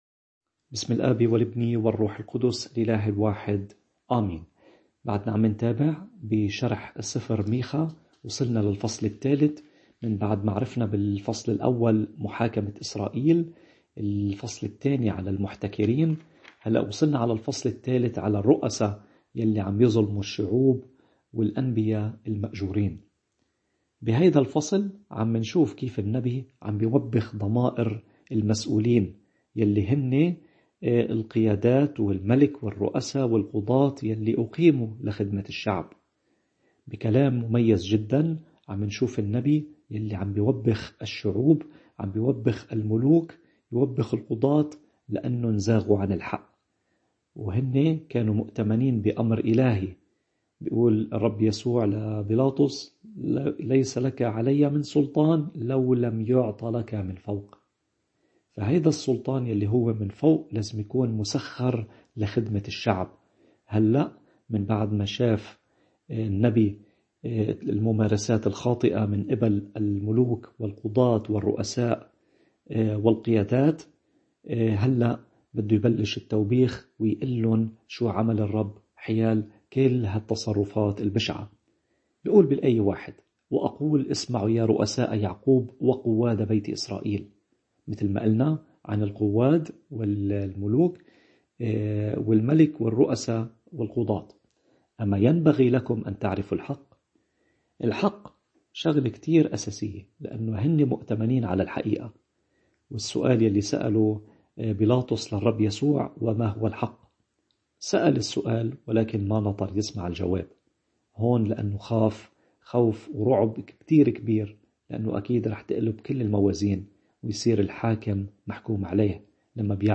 نور ونار لشرح الإنجيل المقدّس، عظات، مواضيع وأحاديث روحيّة، عقائديّة ورهبانيّة…